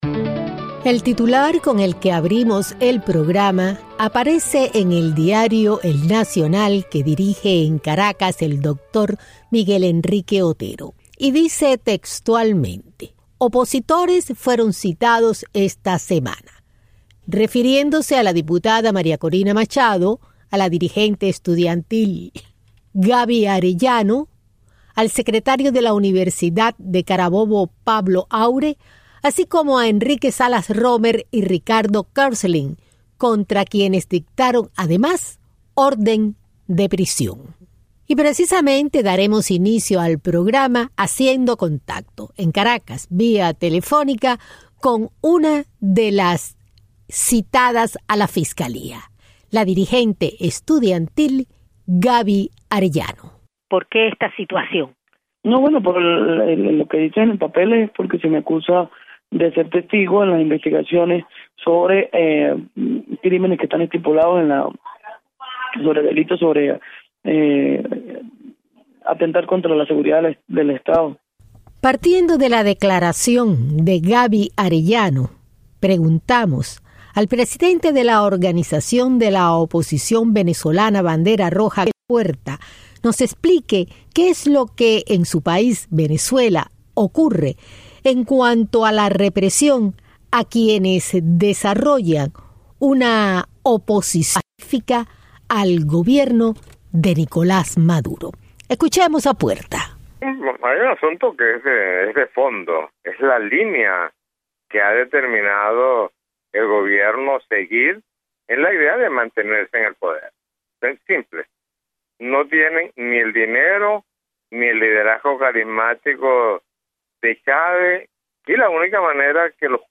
Entrev. dirigente venezolano